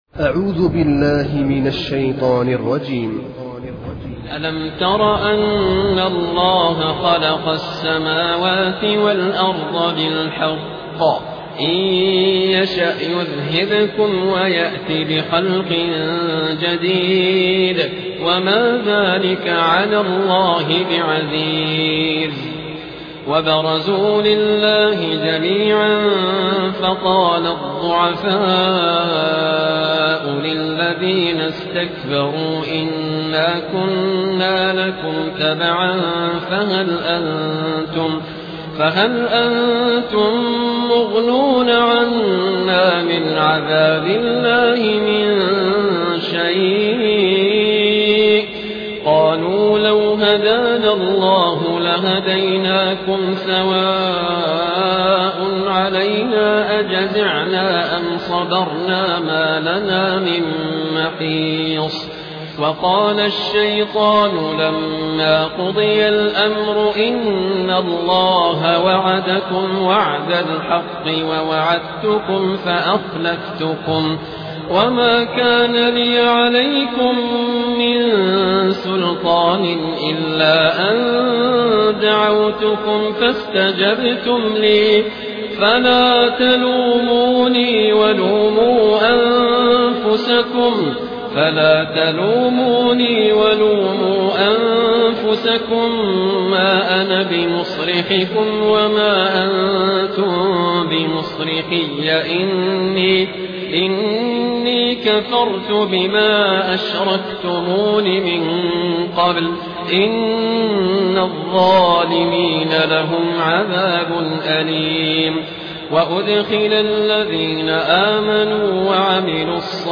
Quran recitations
Humble, distinctive recitations